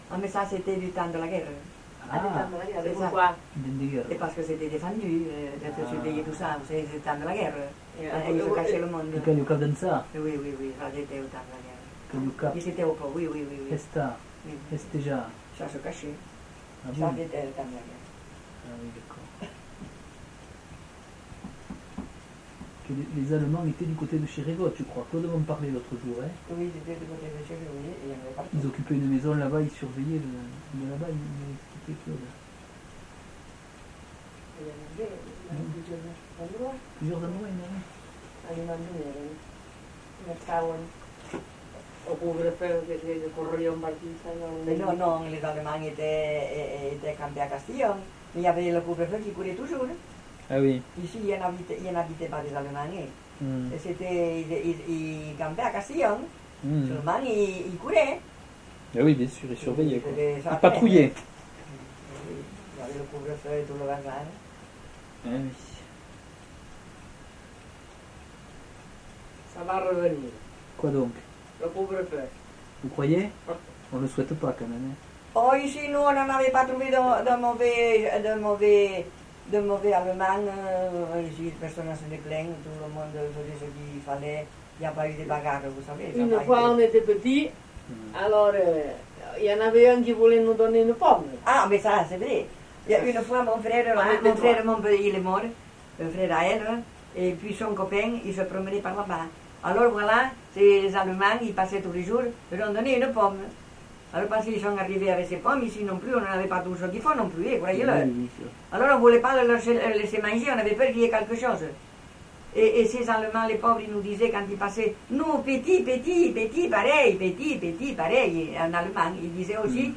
Lieu : Ayet (lieu-dit)
Genre : témoignage thématique